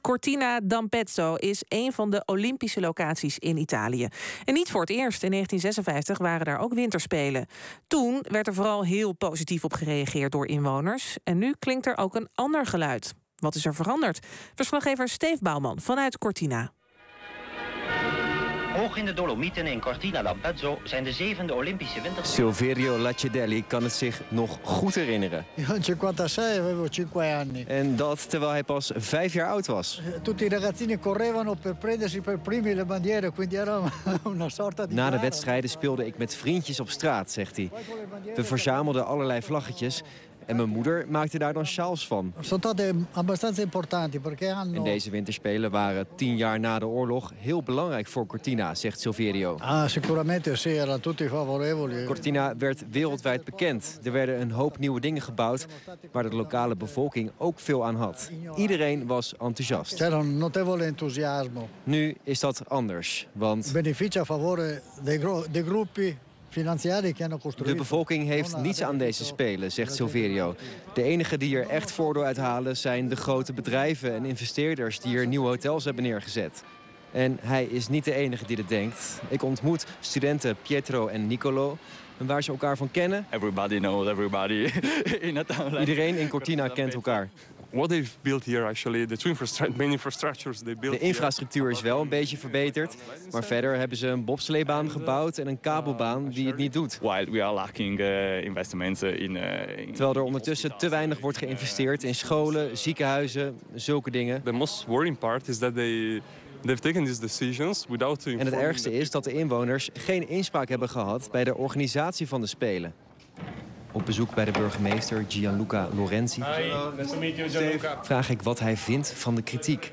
Een reportage voor het NOS Radio 1 Journaal uit Cortina d’Ampezzo. Bewoners zijn kritisch over de Spelen, de burgemeester ziet enkel voordelen en vindt dat ze moeten stoppen met klagen: